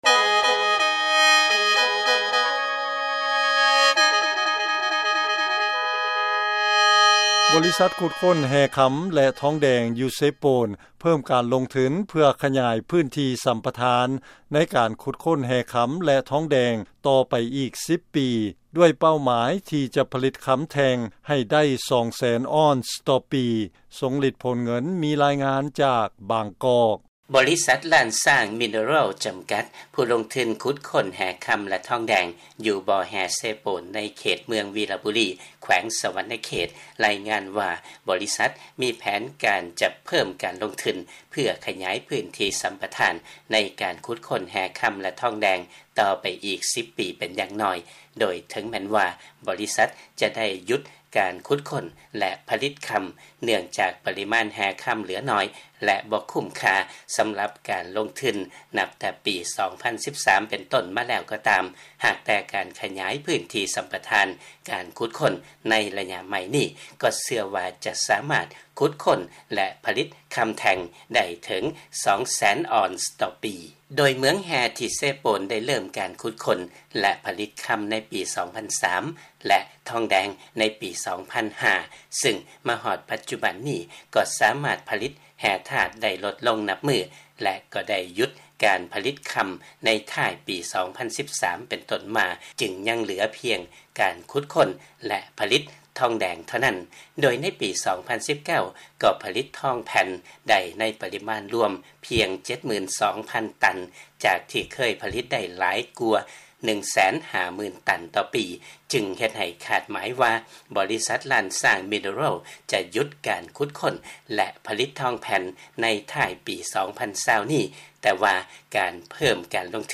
ເຊີນຟັງລາຍງານການຂະຫຍາຍພື້ນທີ່ສຳປະທານຂຸດຄົ້ນແຮ່ຄຳ ແລະທອງແດງຂອງ ບລສ Lane Xang Mineral